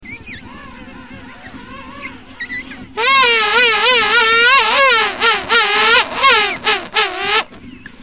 Colonie de manchots royaux. Baie Larose.
Ecoutez l'ambiance d'une colonie (source : AMAPOF).